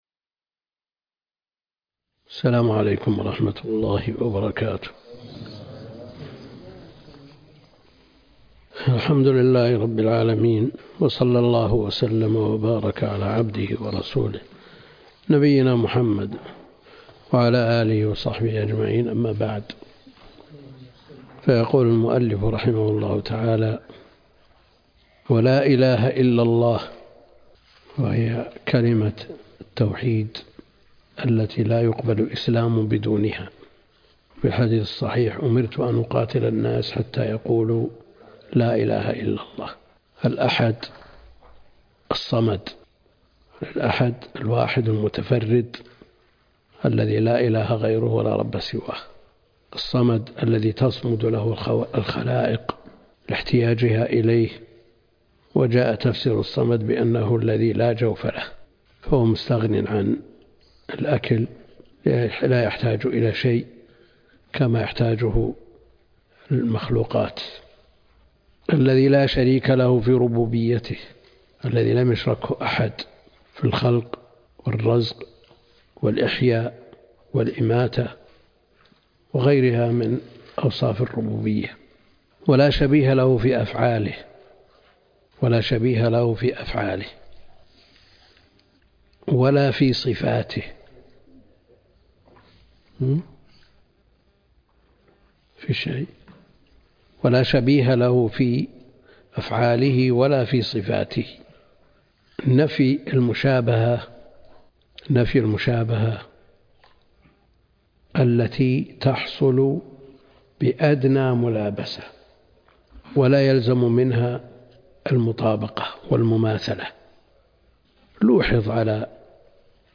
الدرس (3) شرح نونية ابن القيم